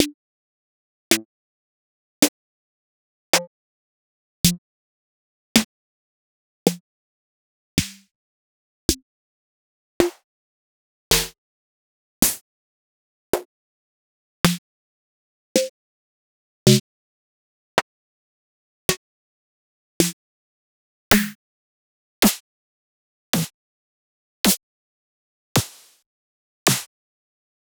Синтез Hi Hats
Снейры сухие, понятно надо реверок, по вкусу еку, гейт, компрессия итд.